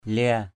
/lia:/ (t.) oằn, trĩu = incurver, courber. lia dhan l`% DN oằn nhánh cây, nhánh cây trĩu xuống = incurver une branche. kayau baoh ralo lia dhan ky~@ _b<H r_l%...